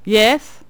cudgel_select7.wav